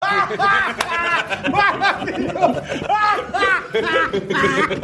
Play, download and share bola rindo original sound button!!!!
bola-rindo.mp3